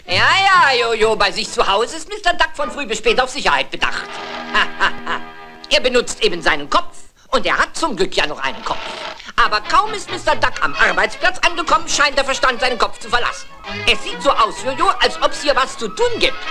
Von allem Anderen abgesehen hört man bei dem Gesuchten einen deutlichen berliner Einschlag.